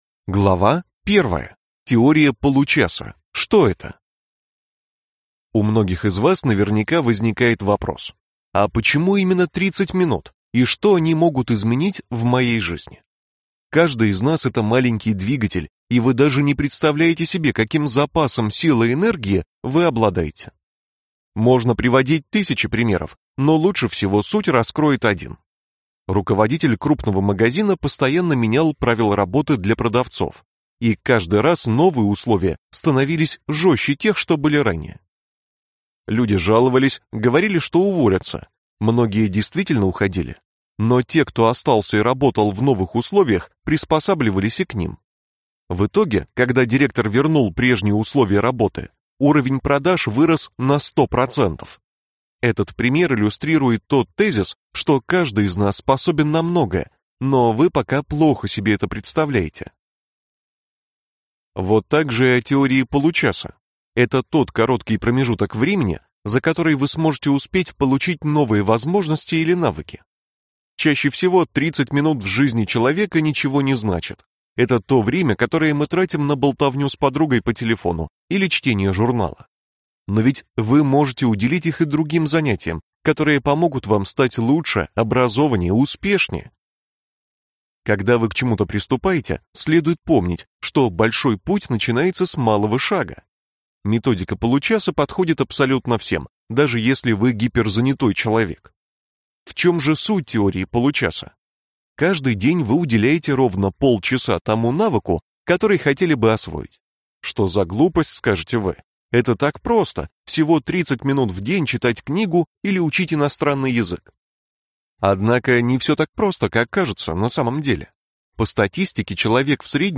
Аудиокнига Теория получаса: как похудеть за 30 минут в день | Библиотека аудиокниг